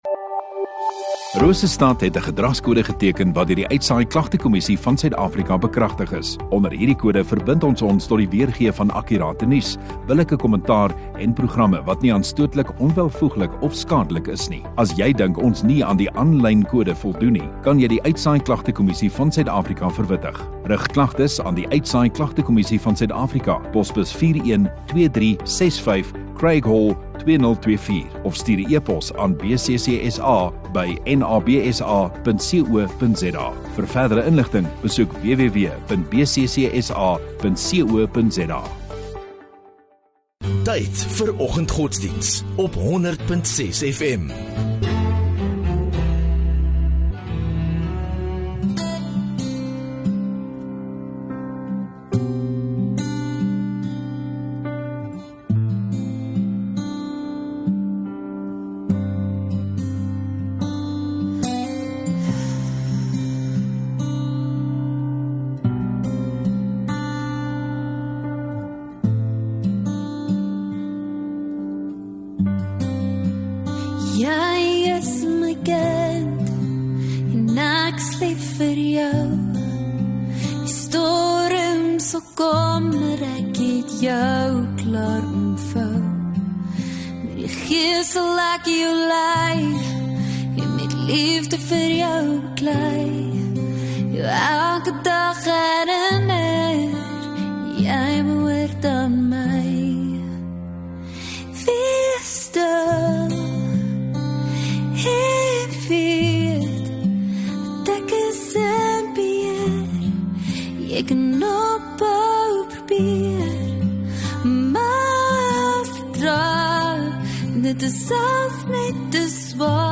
11 Feb Dinsdag Oggenddiens